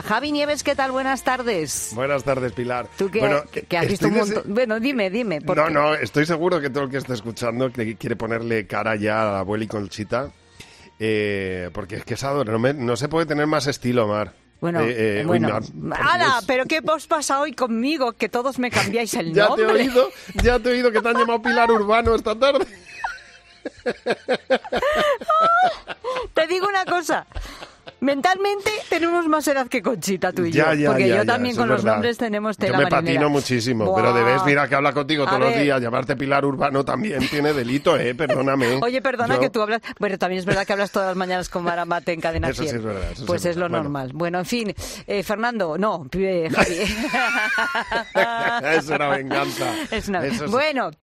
Pilar Cisneros detiene 'La Tarde' tras escuchar cómo le llama Javi Nieves en directo: "Te digo una cosa..."